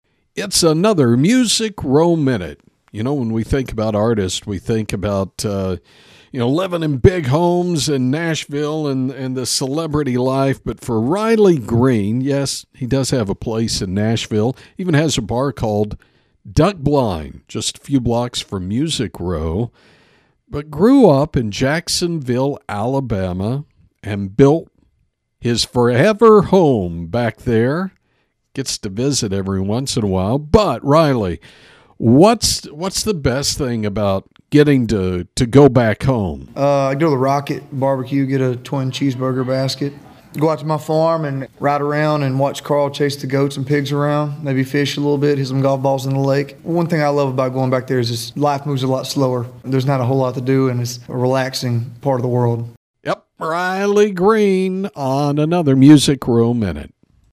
Music Row Minute is a daily radio feature on 106.1FM KFLP